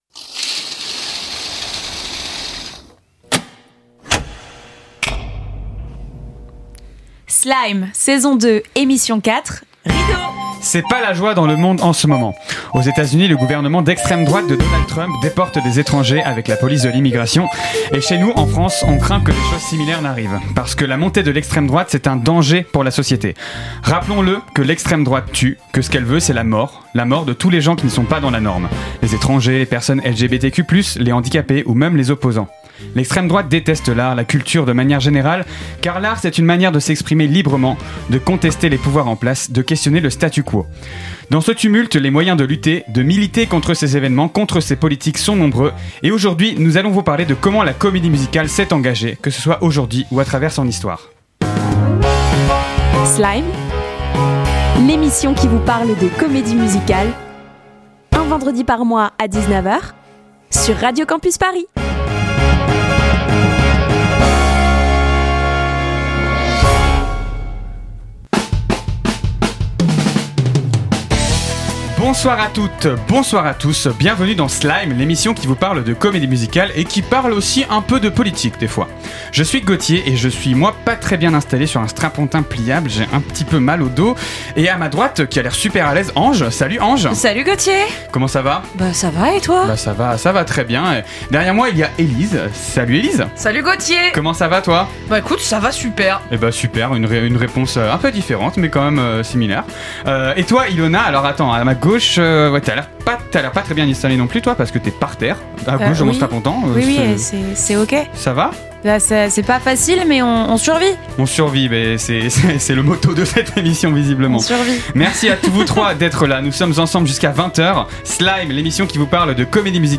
Ce mois-ci dans Slime, l'équiper se réunit pour parler de comment lutter grâce à l'art et à la Comédie Musicale contre le fascisme.
Magazine